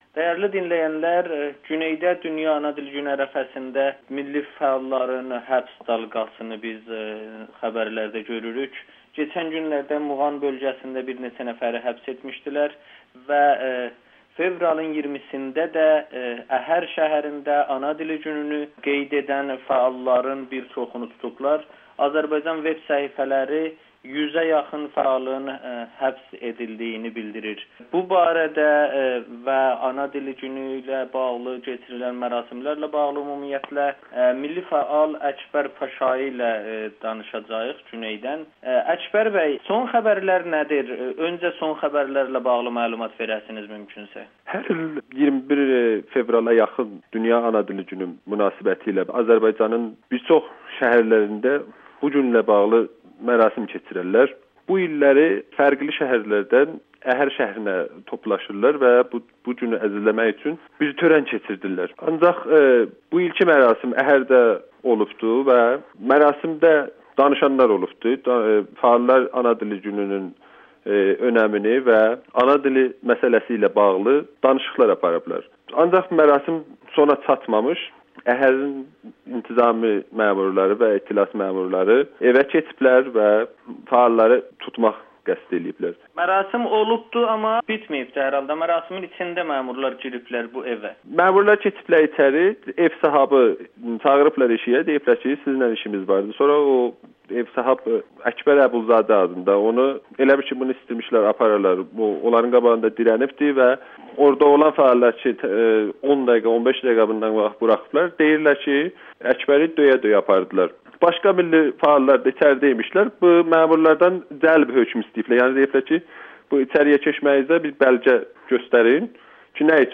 müsahibə